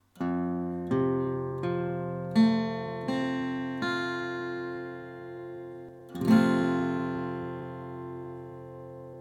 F-Dur (Barré, E-Saite)